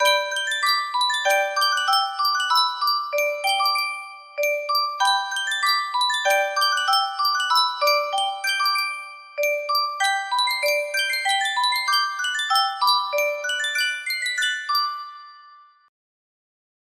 Yunsheng Boite a Musique - Le Chant des Partisans Y456 music box melody
Full range 60